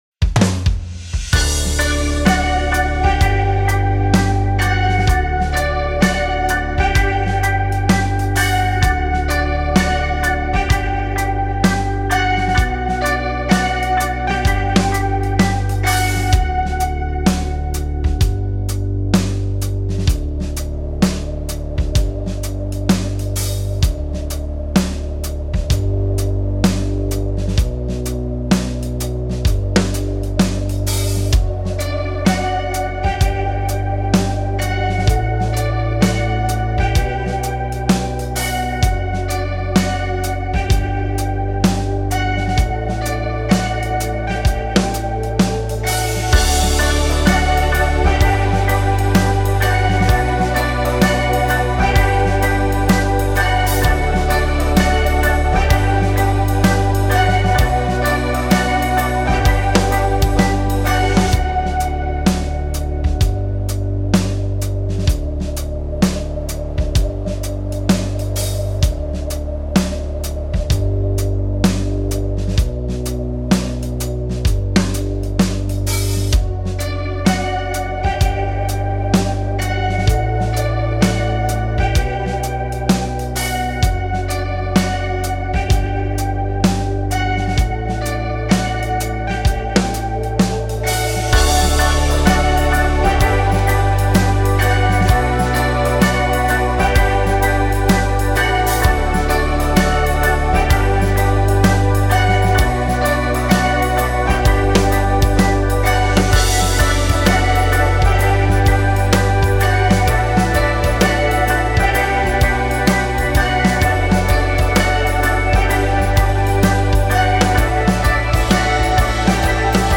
Übungsaufnahmen - Neuanfang
Neuanfang (Playback)
Neuanfang__5_Playback.mp3